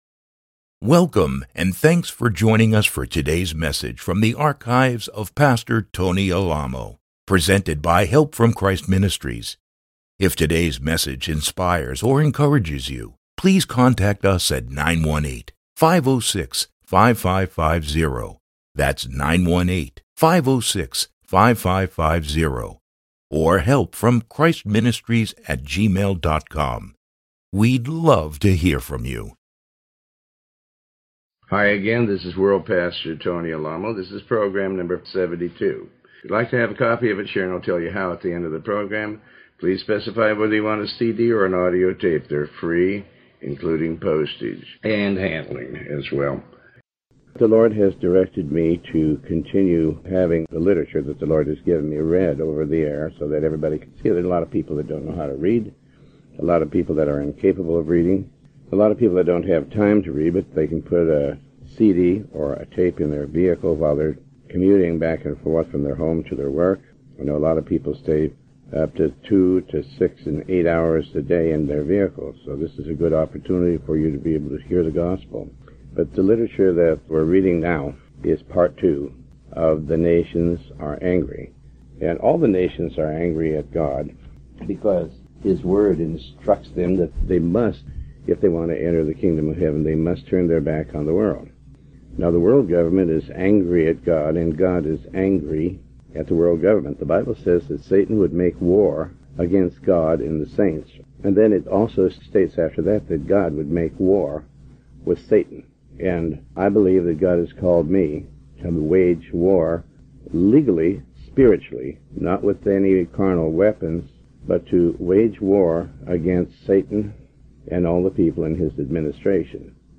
Sermon 72A